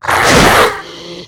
monstermiss.ogg